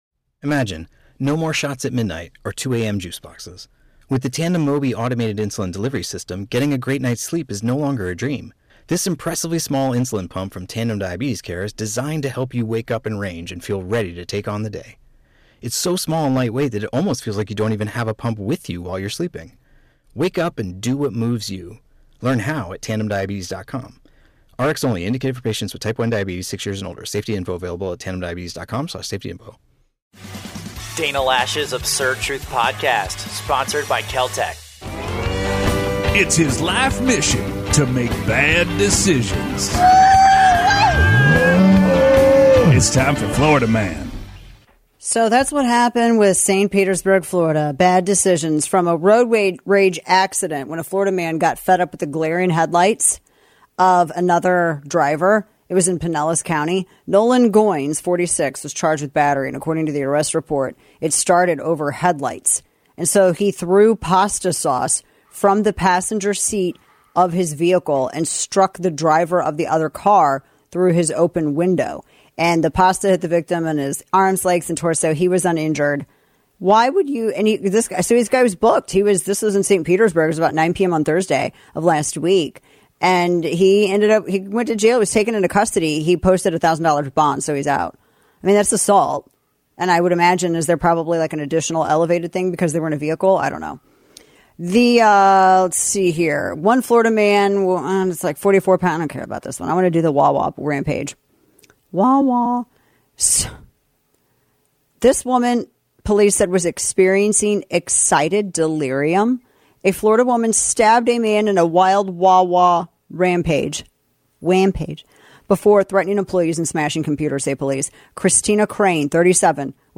Let’s dive into the day’s hottest topics with none other than Dana Loesch on The Dana Show, broadcasting live from KLZ every Monday through Friday at 7 PM.